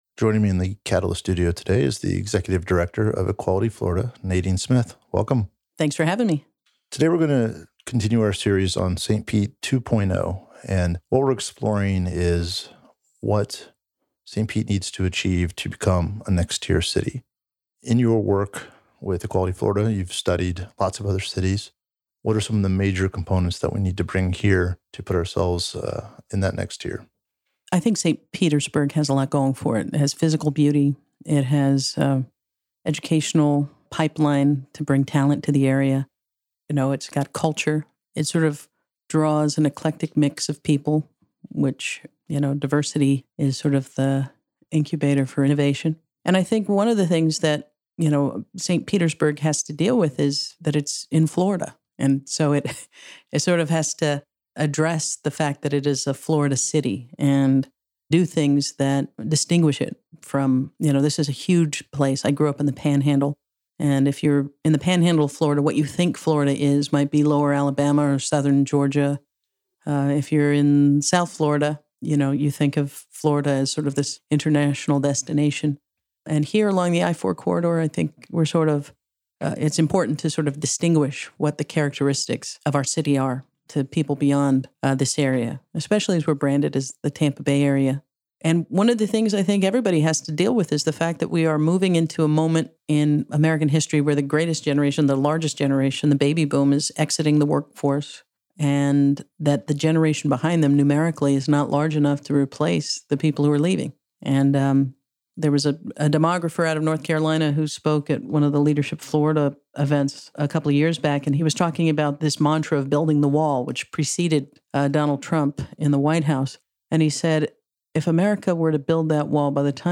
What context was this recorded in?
She stops by the Catalyst studio to share her wisdom and her vision for how St Pete can be a place where everyone is included and valued – and why that prospect is a necessity for us to move to the next tier as a city.